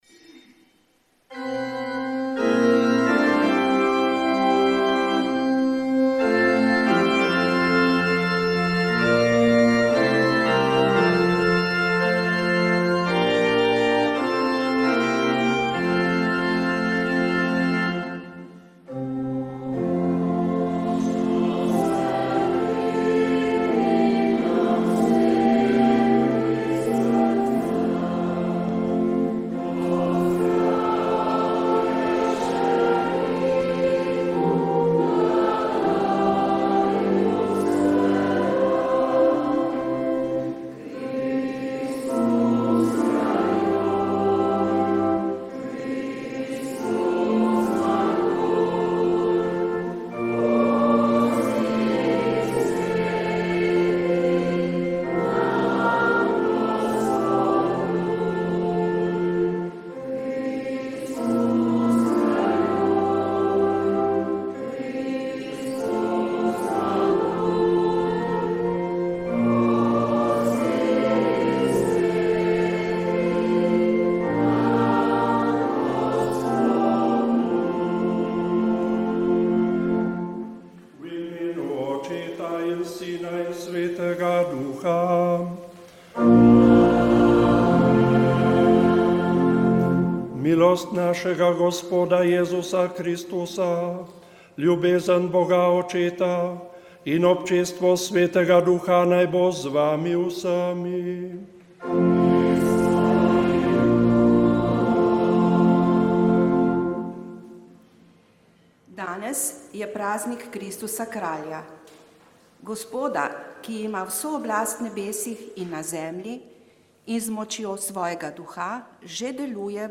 Sv. maša iz kapele romarskega doma v Assisiju dne 21. 3.